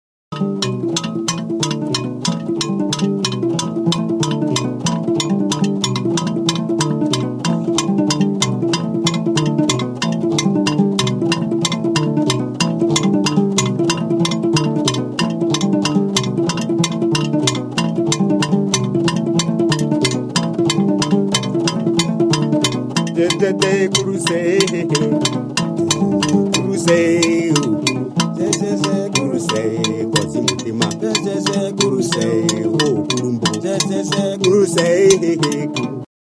Cordófonos -> Pulsados (con dedos o púas)
ÁFRICA -> CHAD
DILLI; Harpa
Bost sokazko harpa da.